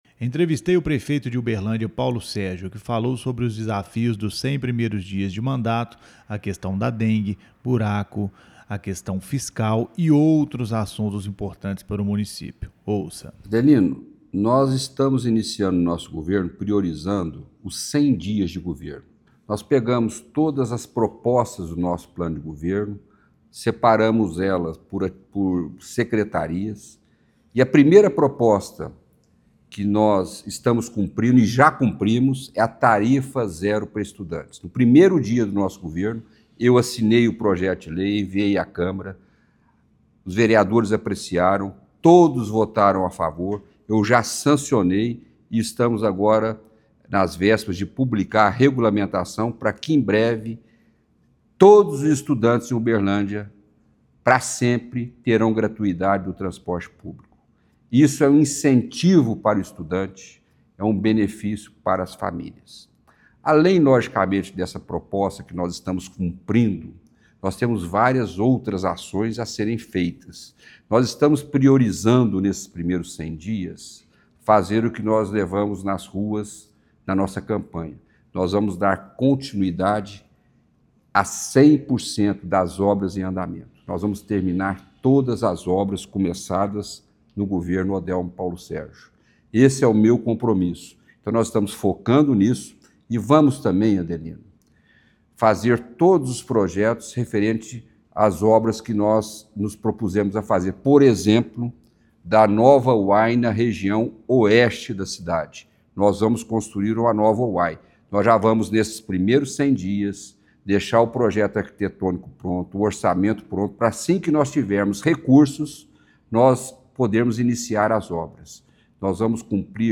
Prefeito Paulo Sérgio discute desafios e futuro de Uberlândia em entrevista exclusiva ao Regionalzão